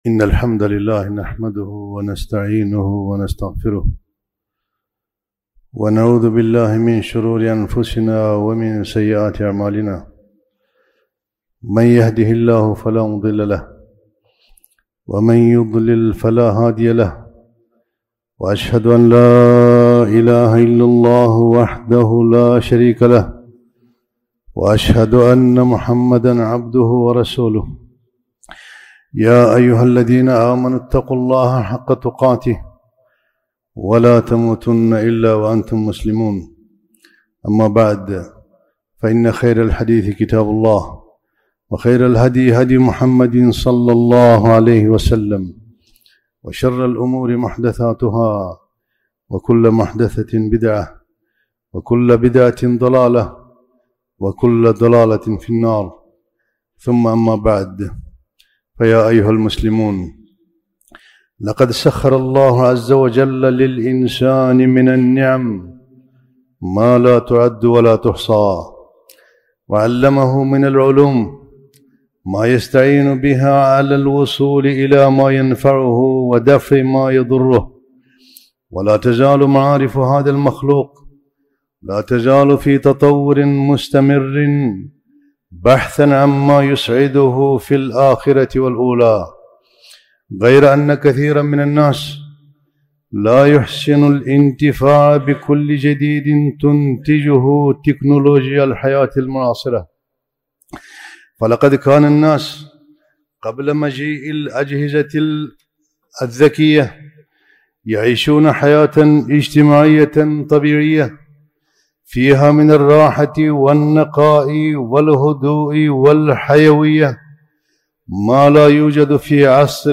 خطبة - خطورة الأجهزة الحديثة على الأطفال